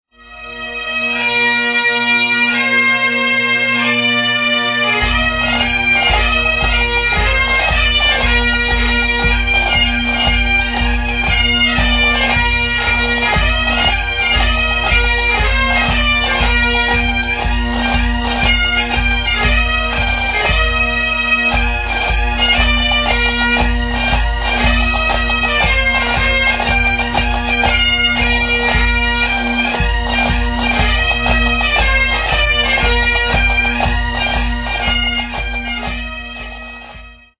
Pipes & Drums
Audio samples are low resolution for browsing speed.
Regimental March / Unlisted
Original Recordings:  Audio Arts Studio, Johannesburg 1984